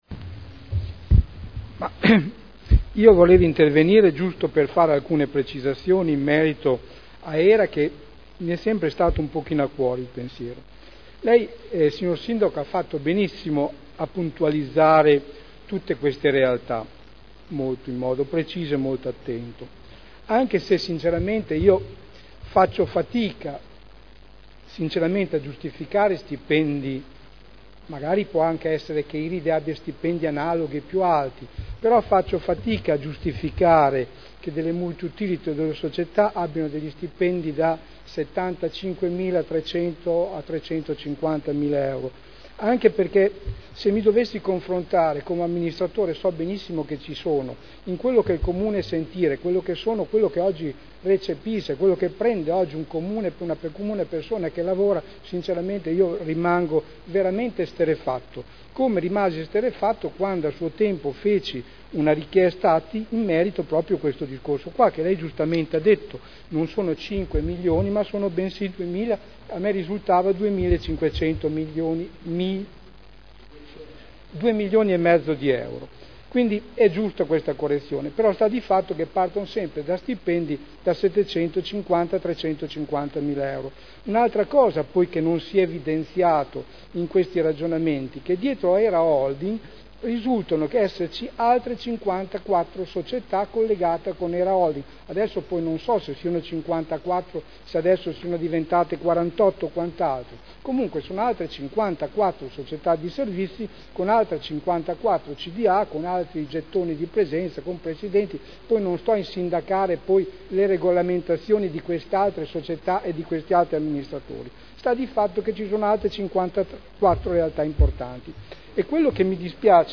Sergio Celloni — Sito Audio Consiglio Comunale
Seduta del 28/02/2011.